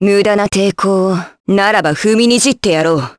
Hilda-Vox_Skill4_jp.wav